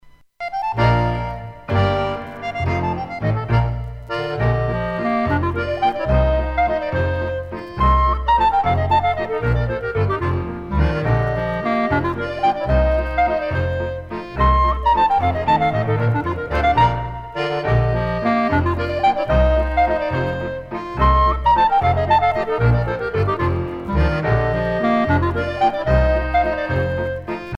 danse : ländler